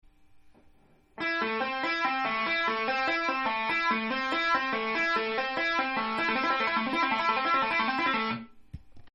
ライトハンド奏法フレーズ４
ライトハンド奏法フレーズの醍醐味である音程差を使ったフレーズです、
音程差があるとかなりトリッキーでテクニカルな感じがしますね♪